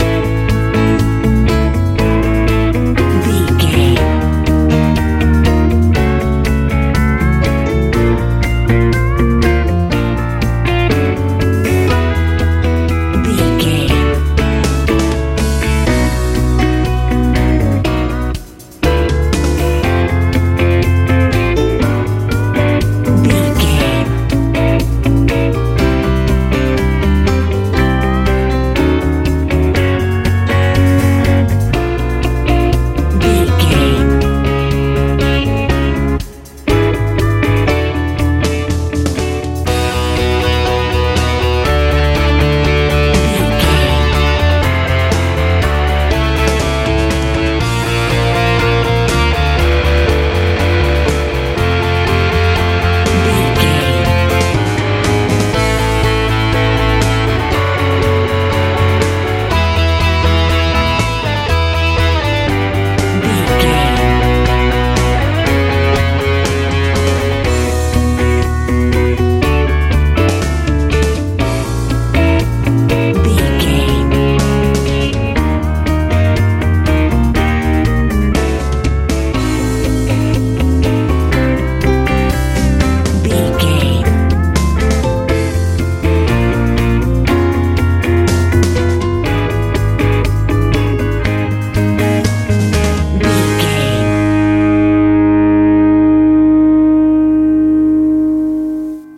med country rock feel
Ionian/Major
C♯
bouncy
lively
electric guitar
piano
bass guitar
drums
sweet
smooth
sentimental
peaceful